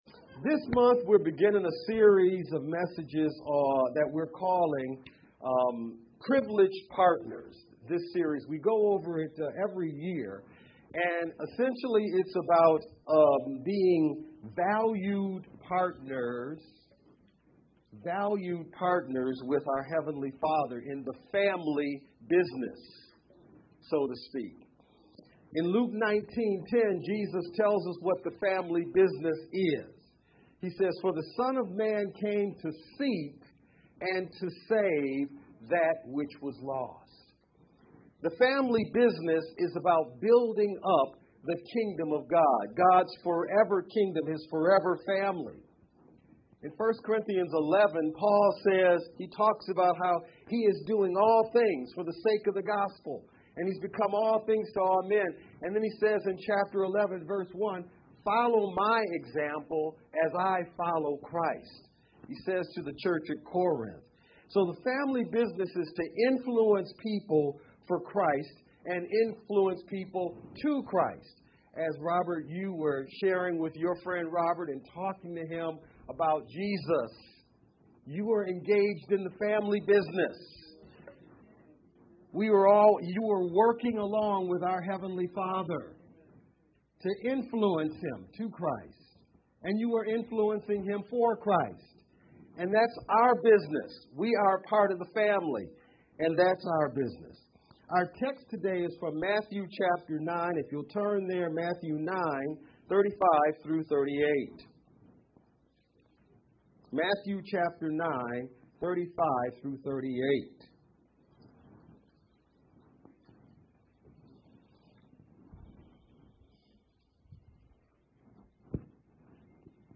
3-5-VBCC-Sermon-edited-sermon-only-MP3-CD.mp3